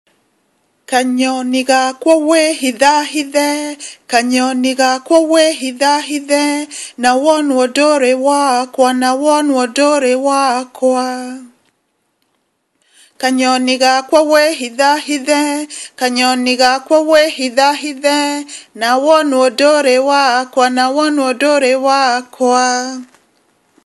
Kanyoni gakwa wihithahithe - Canciones infantiles kenyanas - Kenya - Mamá Lisa's World en español: Canciones infantiles del mundo entero
kanyoni_gakwa_kikuyu.mp3